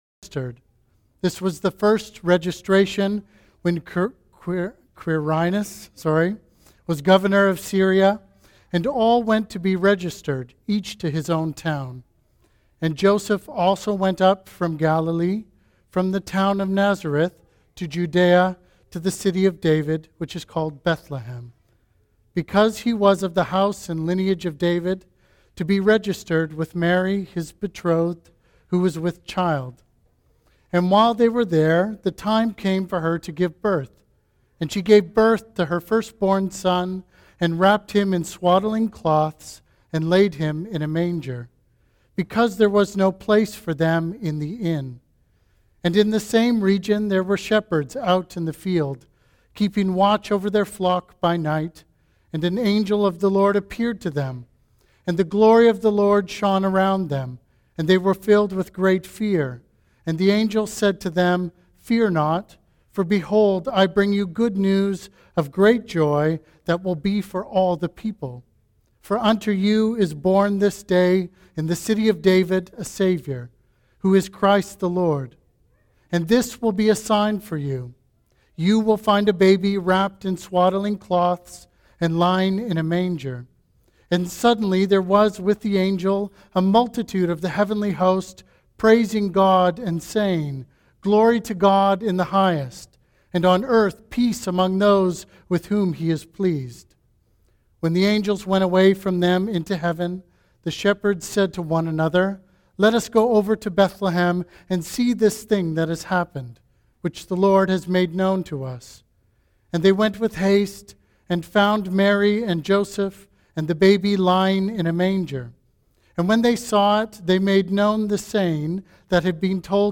Sermons | Christ's Church Oceanside
Christmas Eve 2025